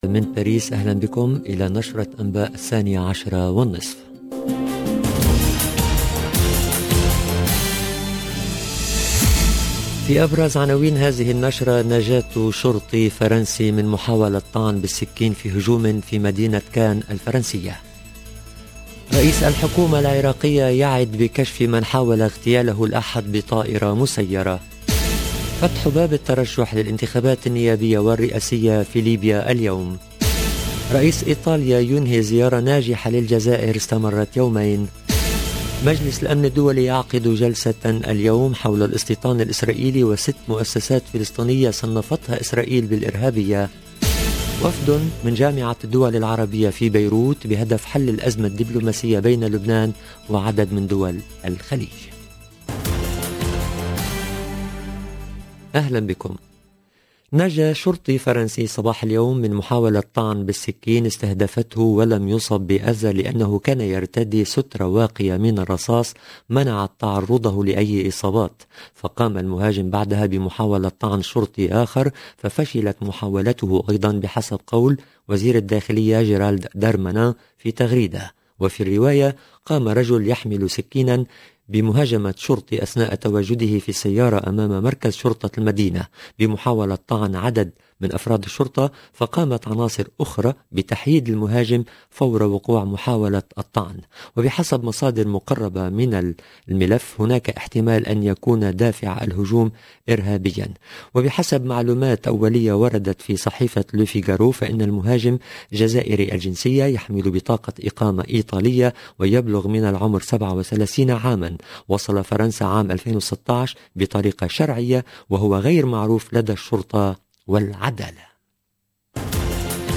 LE JOURNAL DE 12H30 EN LANGUE ARABE DU 8/11/21